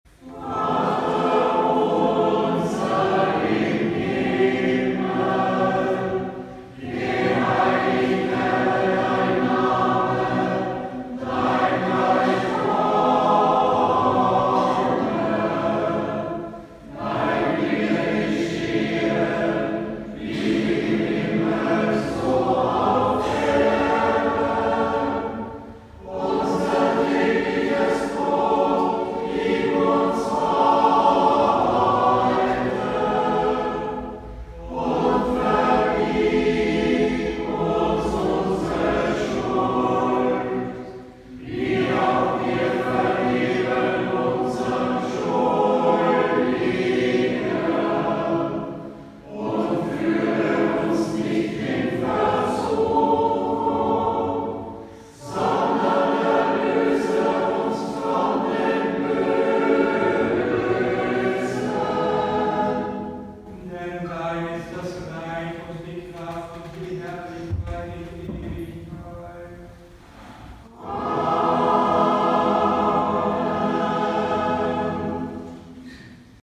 Erstkommunion 22. Mai 2020
Ruprechtskirche
Live-Aufnahme